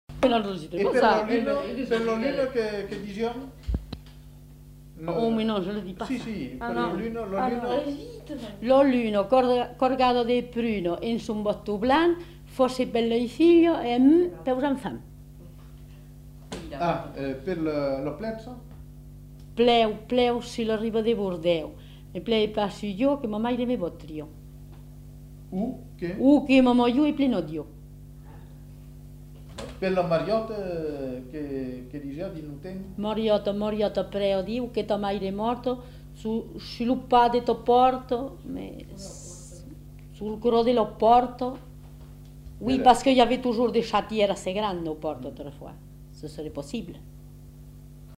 Aire culturelle : Périgord
Lieu : Castels
Genre : forme brève
Effectif : 1
Type de voix : voix de femme
Production du son : récité
Classification : formulette enfantine